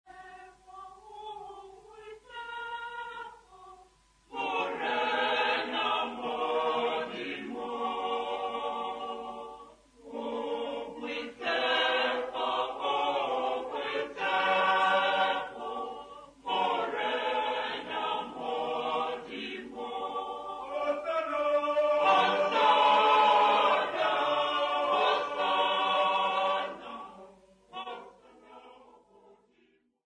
St Anthony's Choir
Folk music
Sacred music
Field recordings
Africa South Africa Ga-Rankuwa, Pretoria sa
Unaccompanied church music festival, liturgical dance and composition of new song
96000Hz 24Bit Stereo